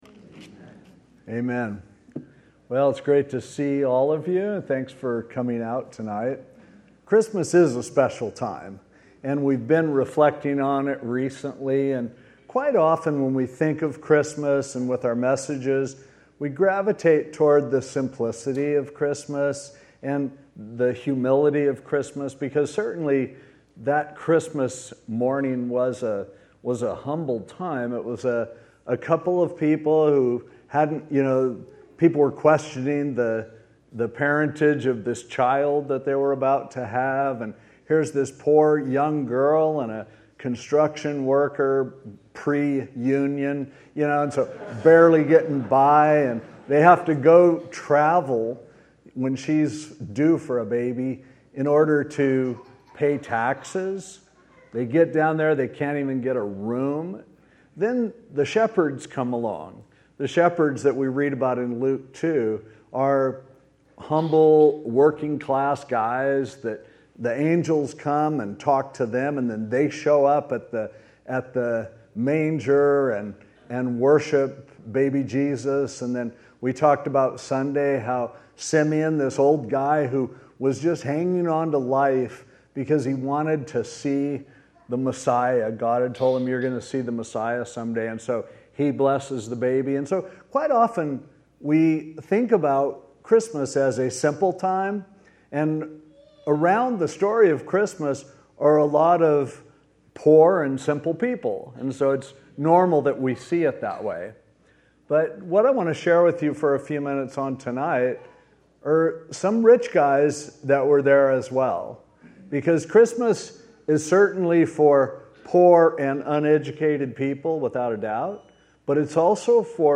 Christmas Eve 2019 (Special Services)